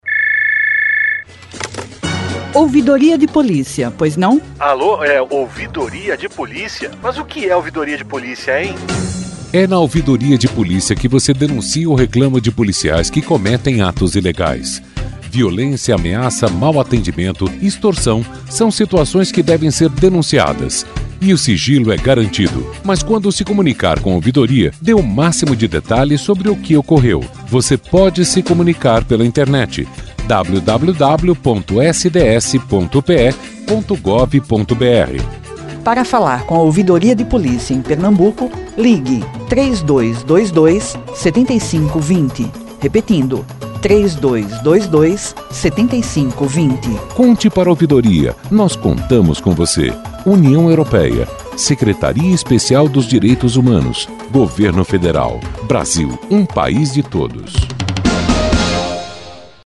Faixa 7 - Spot “Sigilo” (1:00)
faixa 07 - Spot Sigilo PE.mp3